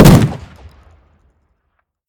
shotgun-shot-8.ogg